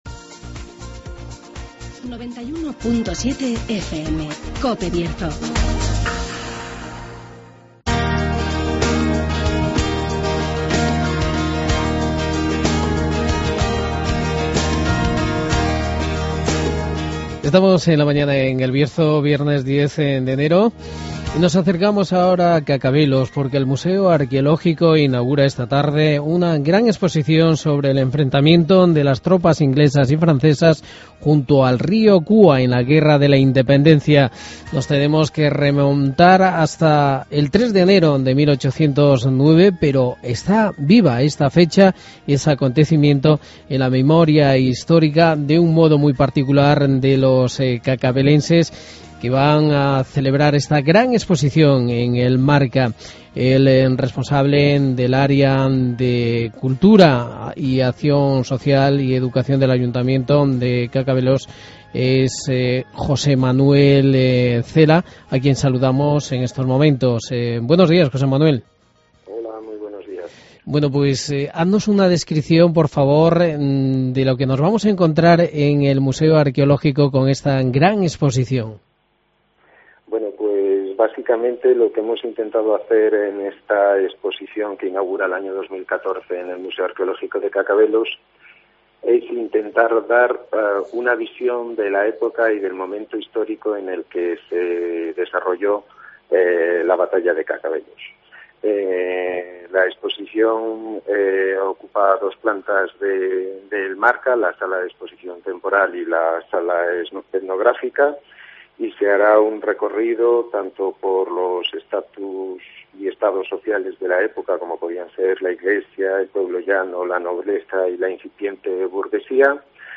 Entrevista a José Manuel Cela
Redacción digital Madrid - Publicado el 10 ene 2014, 14:12 - Actualizado 02 feb 2023, 00:07 1 min lectura Descargar Facebook Twitter Whatsapp Telegram Enviar por email Copiar enlace José Manuel Cela, concejal de Cultura del Ayuntamiento de Cacabelos. Exposición en el MARCA sobre la batalla del río Cúa de la Guerra de la Independencia.